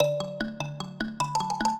mbira
minuet0-5.wav